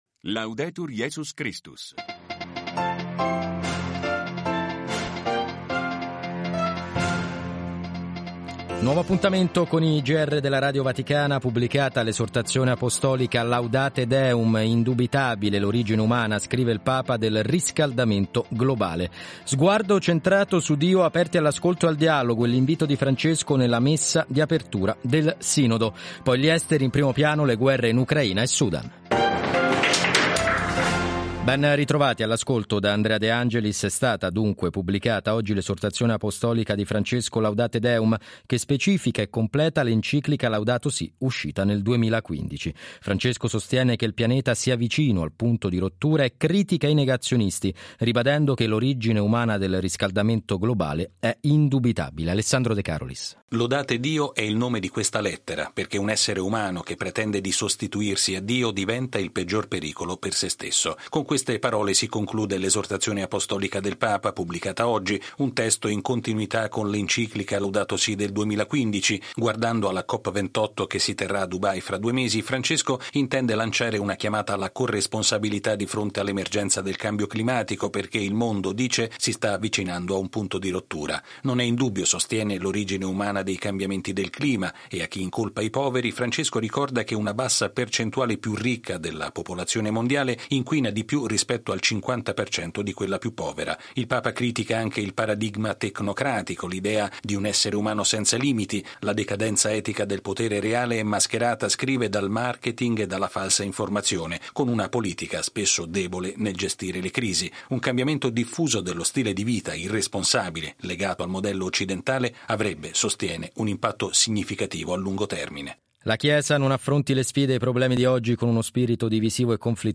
Radio Vaticana - Radiogiornali